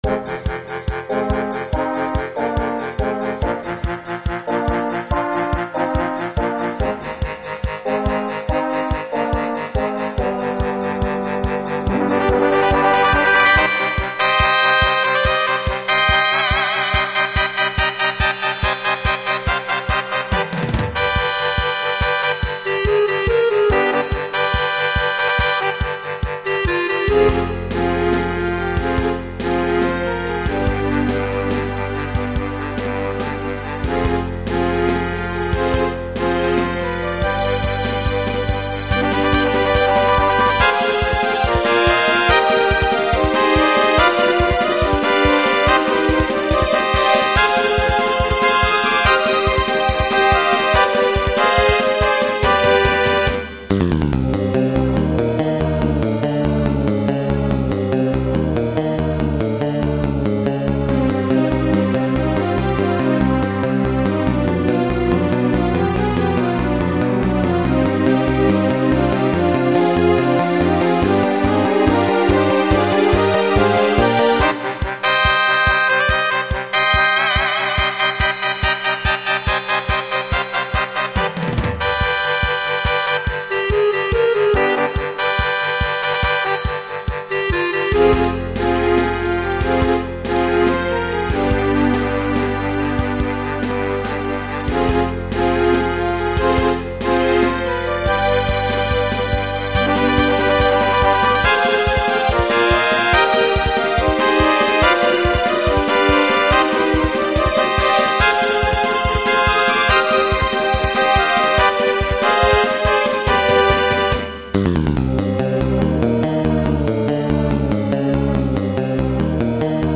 なので、WinGrooveで再生して.mp3にしたものも置いておきます。
鳴っているのはbattle03.midからの低スペックMP3です。
battle03.mp3(2.11MB)次回作っぽい？戦闘、ノれます。